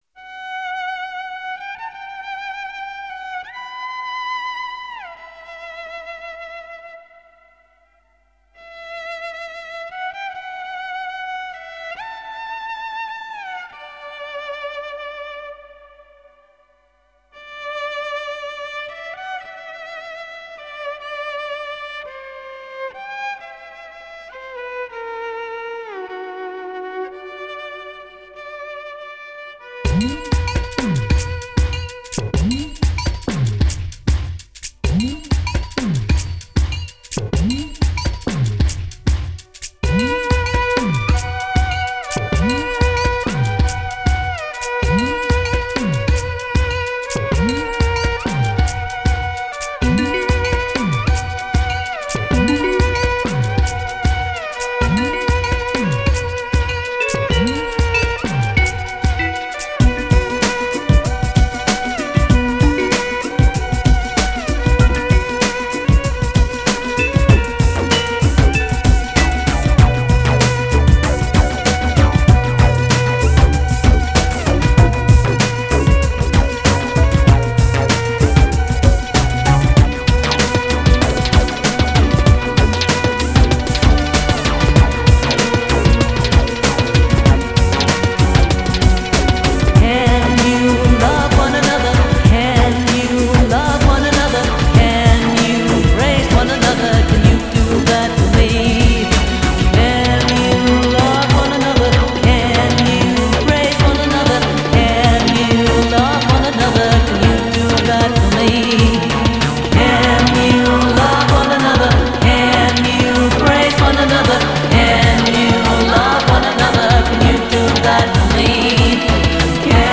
solo dance CD